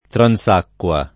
[ tran S# kk U a ]